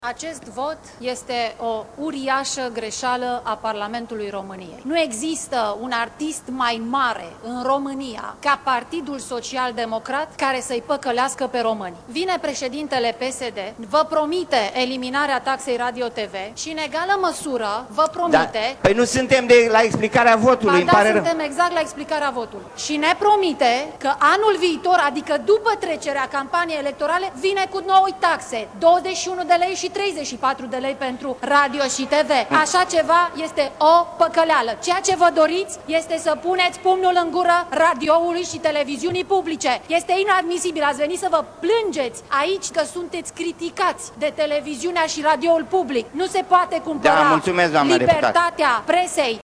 Deputata PNL Andreea Paul i-a acuzat pe social-democrați că, prin eliminarea taxei radio-TV încearcă să dea o lovitură posturilor publice de radio și televiziune. Supărat, președintele Camerei, Florin Iordache, i-a închis microfonul: